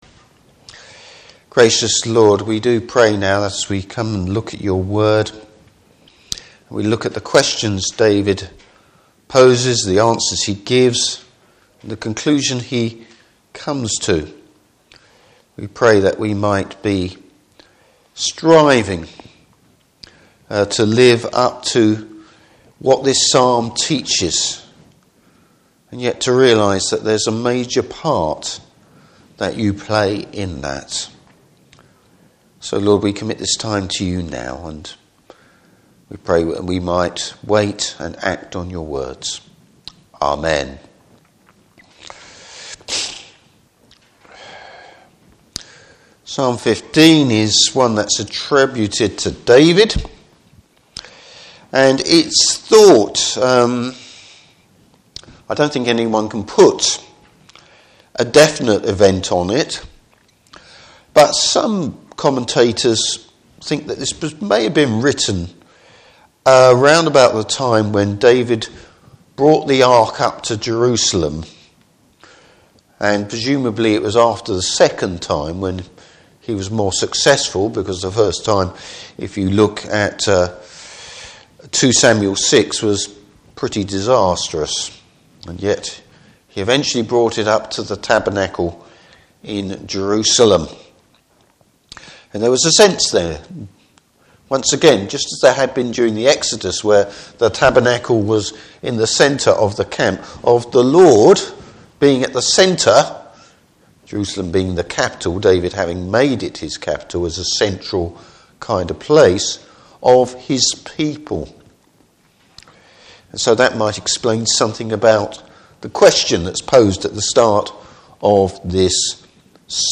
Service Type: Evening Service David asks a big and important question.